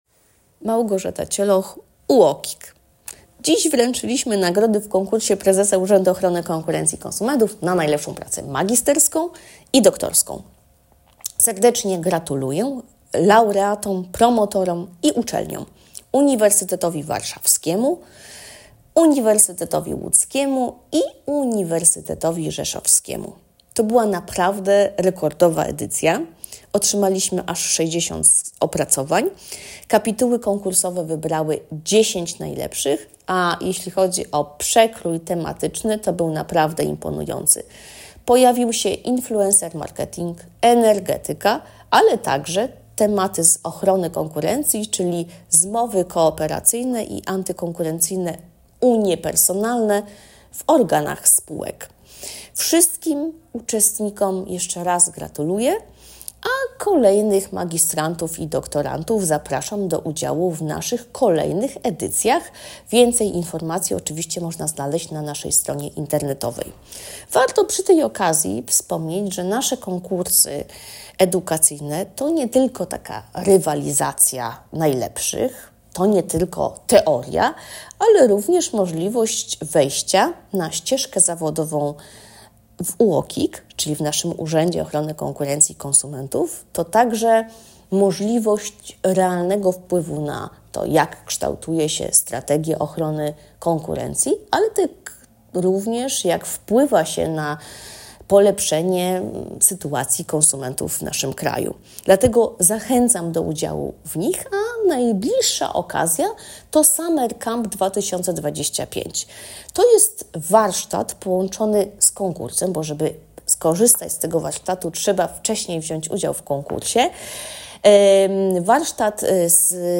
Wypowiedź